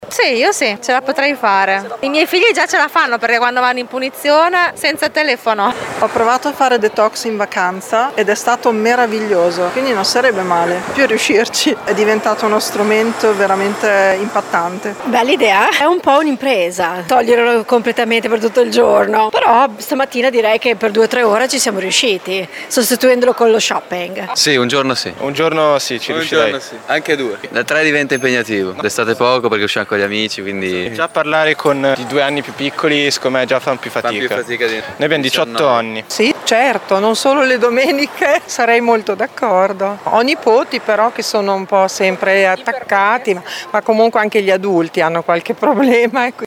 VOX-DETOX-TELEFONO.mp3